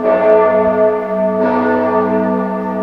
Bigbell
BigBell.wav